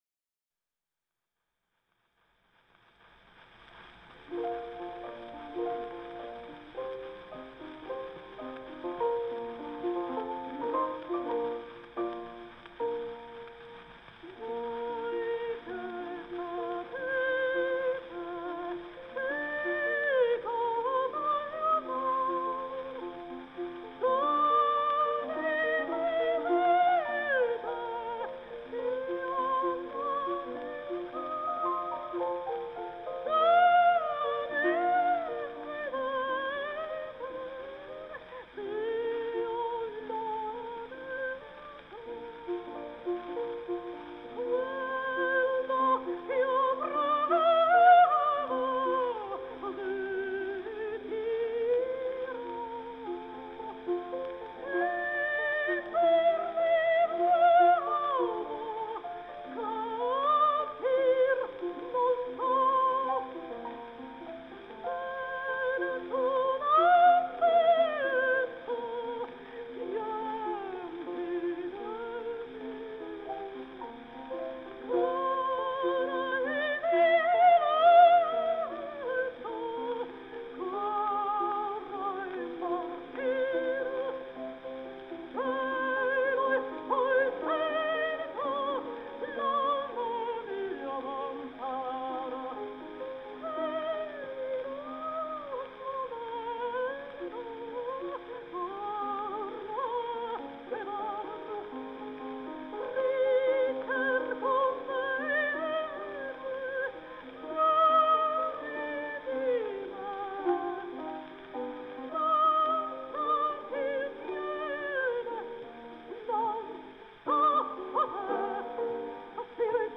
Крупнейшая певица 19 в., обладала виртуозной вок. техникой.